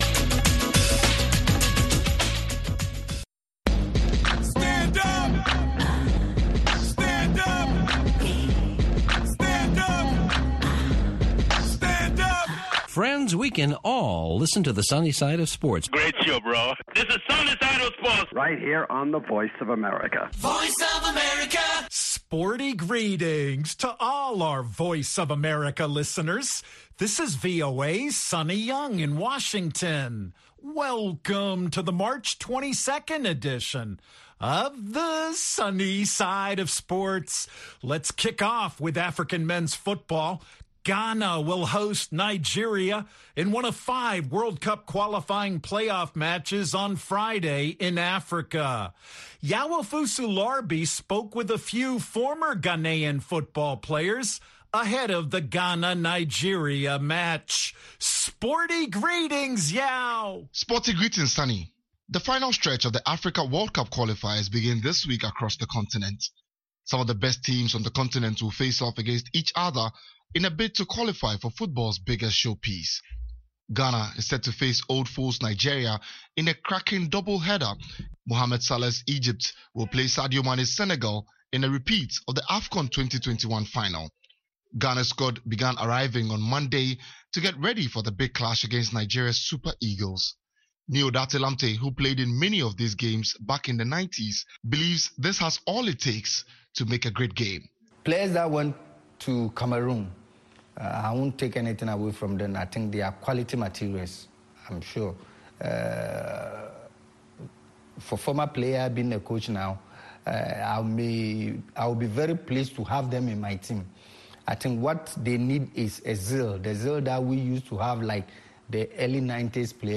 We'll hear Muntari's comments on Tuesday's show.